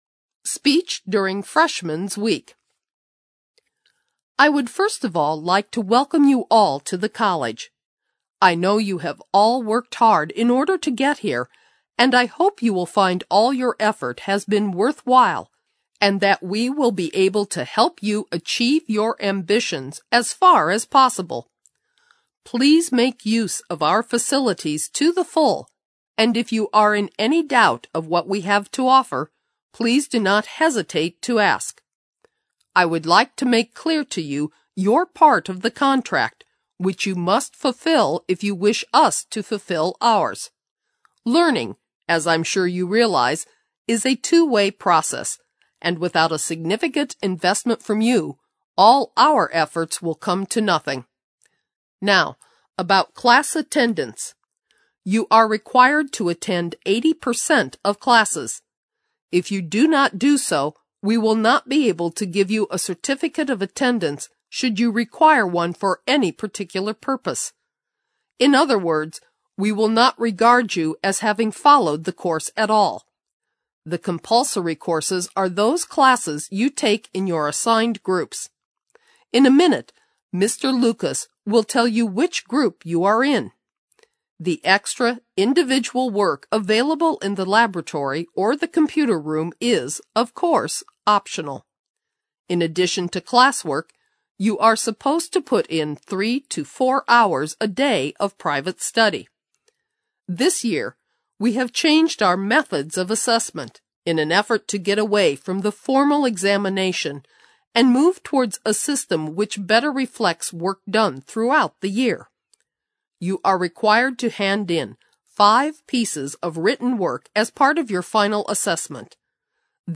Speech during Freshmen's Week大学新生周演讲